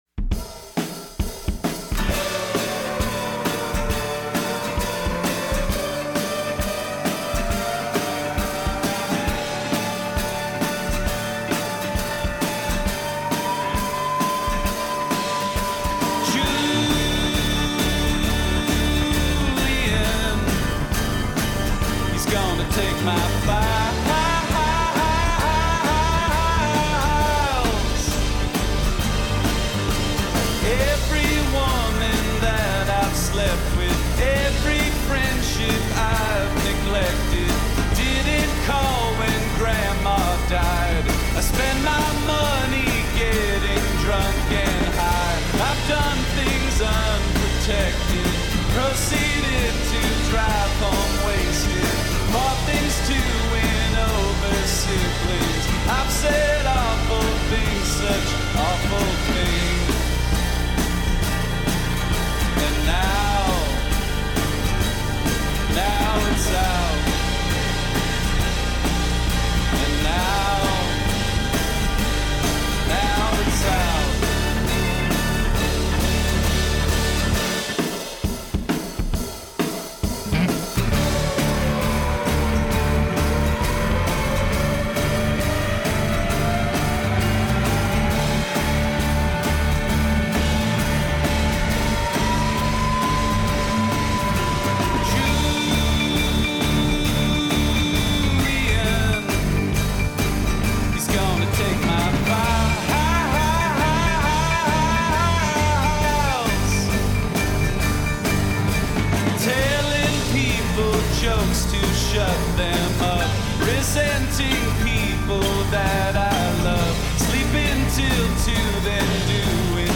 Stomping rock drives the debauchery confession